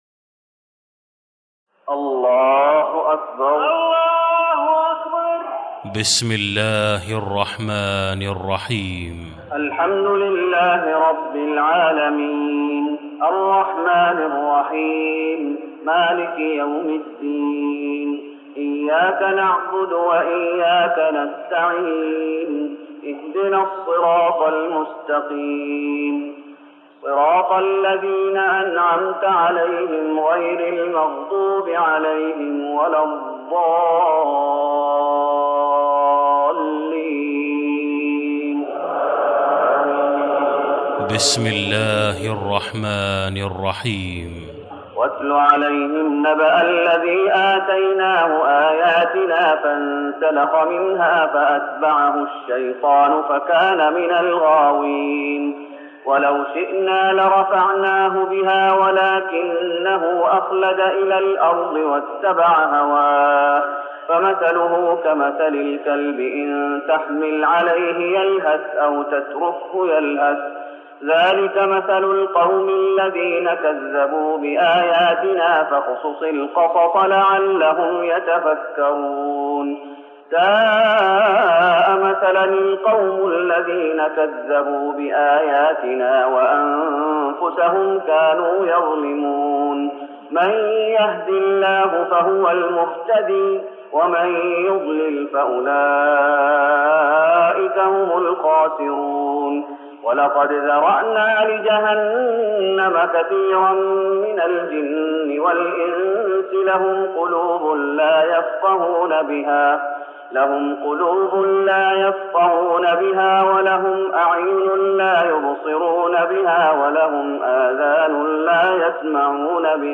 تراويح رمضان 1414هـ من سورة الأعراف (175-206) Taraweeh Ramadan 1414H from Surah Al-A’raf > تراويح الشيخ محمد أيوب بالنبوي 1414 🕌 > التراويح - تلاوات الحرمين